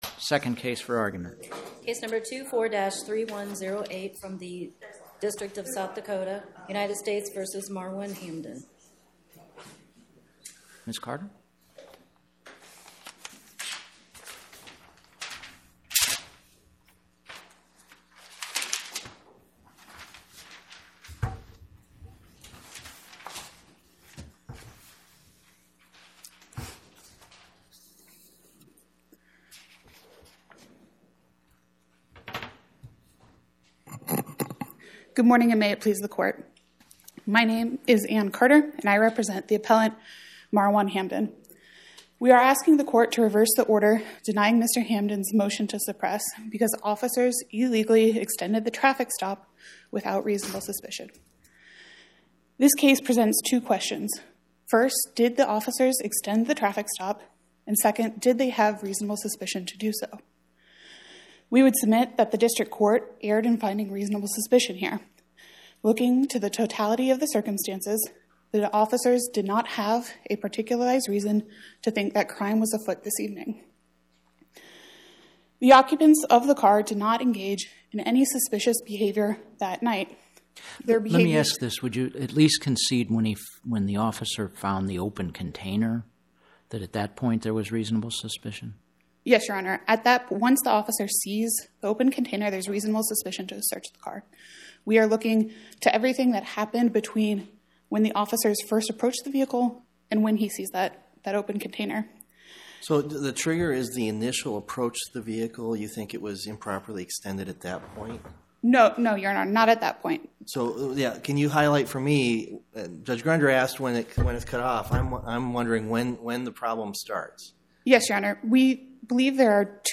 Oral argument argued before the Eighth Circuit U.S. Court of Appeals on or about 10/23/2025